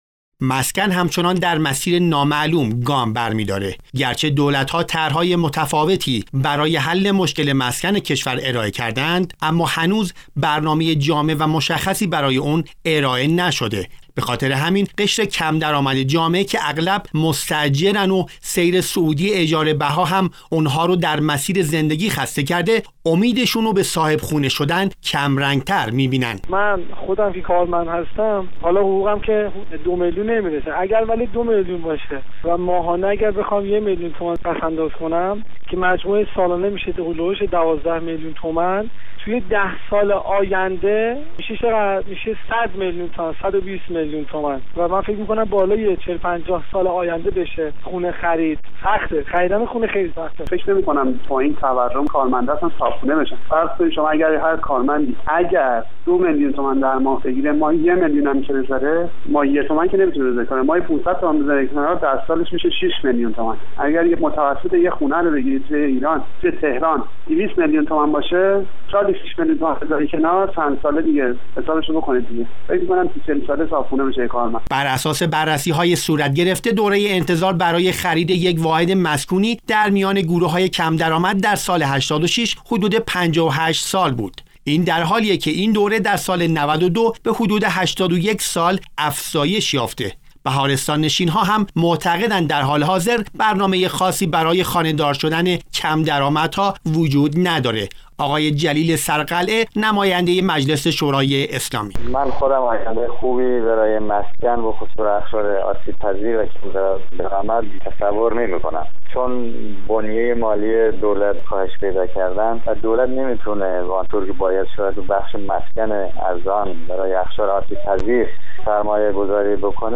گزارش "شنیدنی" از رویای خانه دار شدن افراد کم درآمد - تسنیم